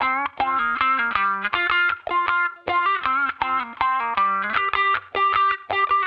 Index of /musicradar/sampled-funk-soul-samples/79bpm/Guitar
SSF_StratGuitarProc2_79G.wav